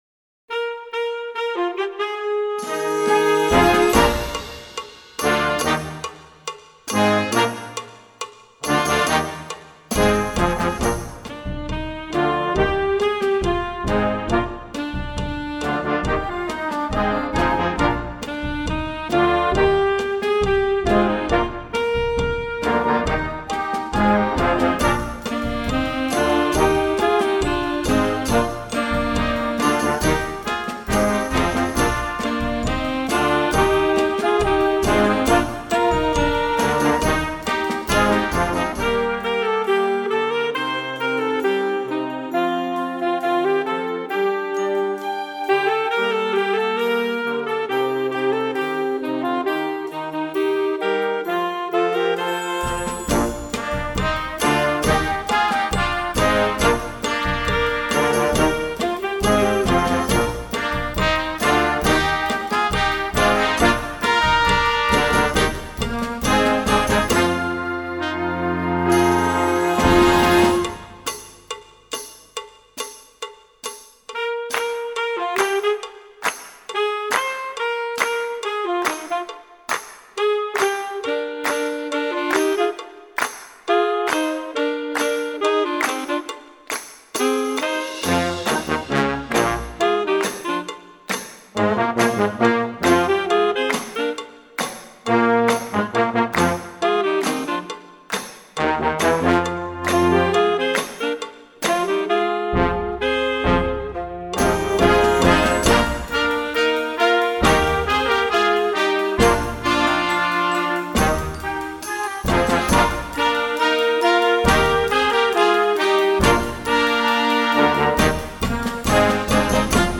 Voicing: Saxophone Section w/ Band